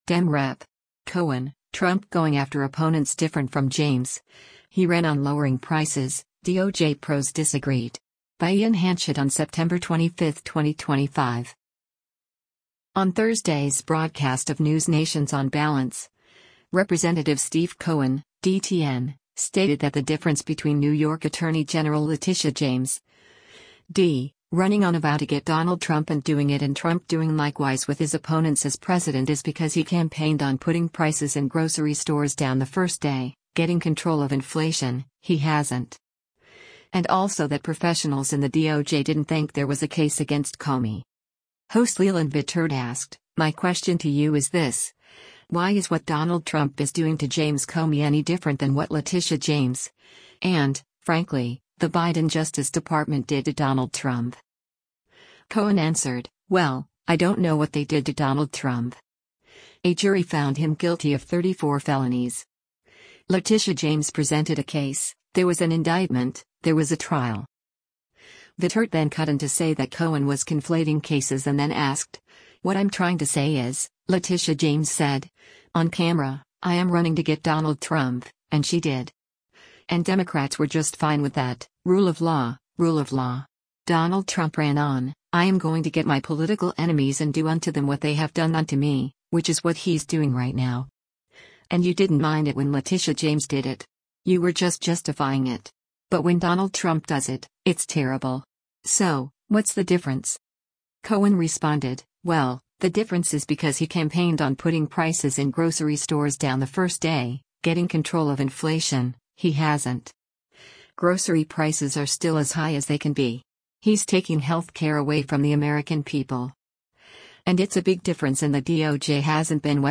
Host Leland Vittert asked, “My question to you is this: Why is what Donald Trump is doing to James Comey any different than what Letitia James, and, frankly, the Biden Justice Department did to Donald Trump?”